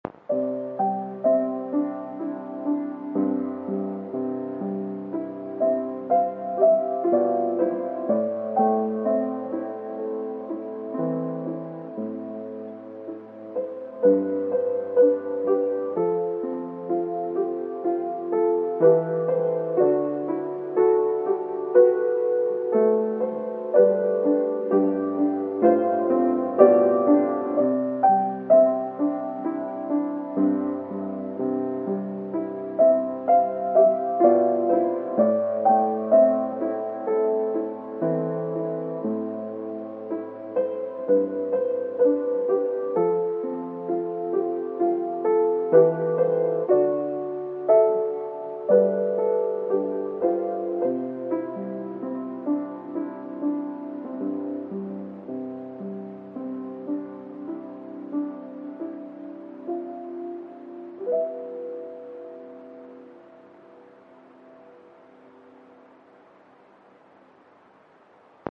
>>MP3<< → アップライトピアノでのマイクによるアナログ録音
言い訳 オープン記念は絶対コレ!と決めていたのに、最後んとこグダグダorz